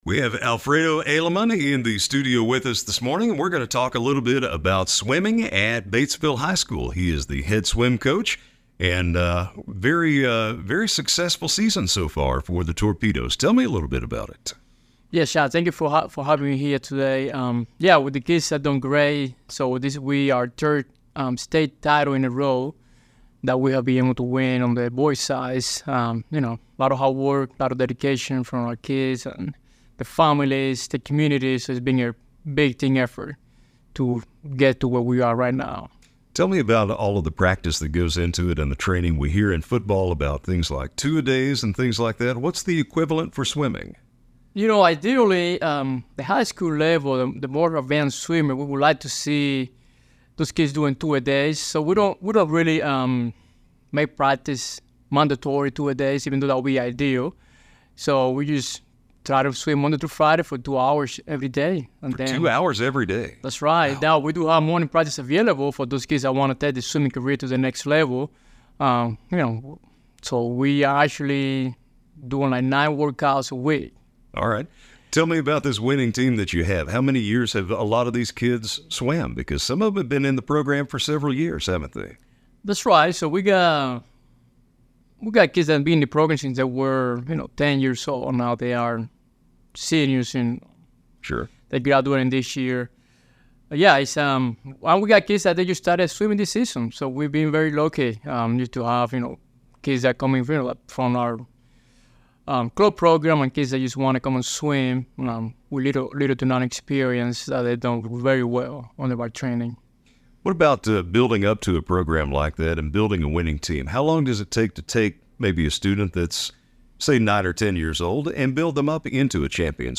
in the Greenway Equipment studio on Arkansas 103.3 KWOZ.